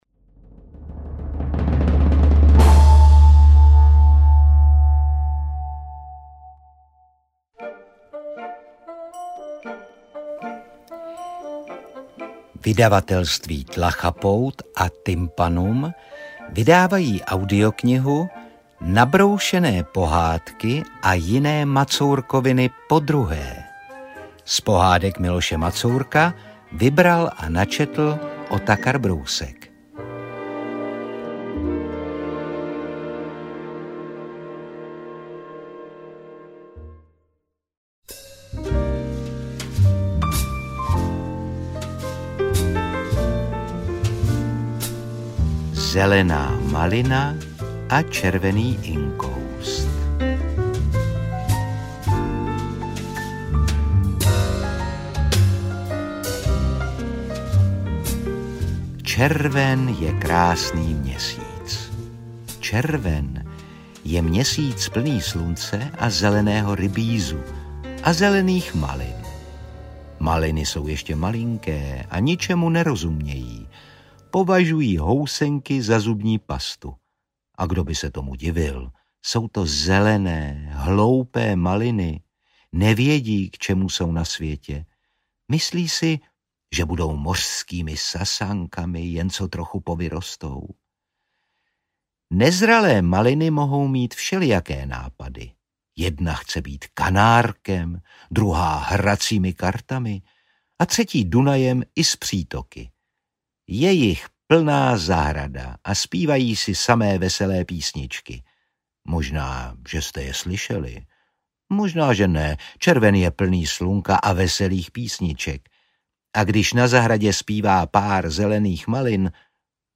Interpret:  Otakar Brousek
AudioKniha ke stažení, 18 x mp3, délka 1 hod. 59 min., velikost 108,6 MB, česky